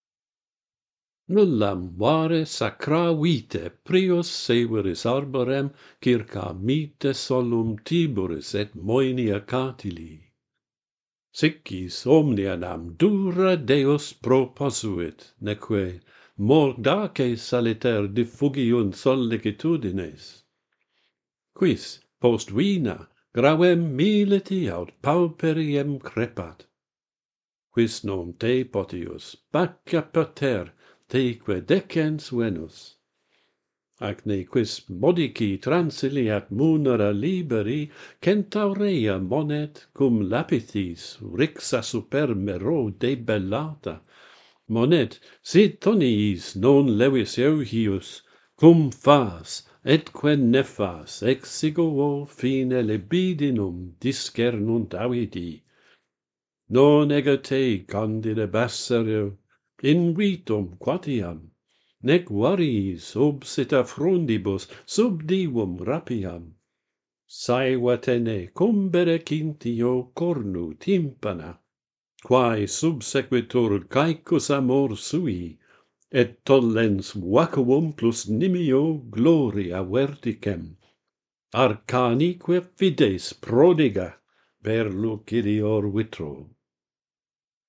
The pleasures and dangers of wine - Pantheon Poets | Latin Poetry Recited and Translated
The poem is written in greater Asclepiads, the uncommon longest form of the Asclepiadic line.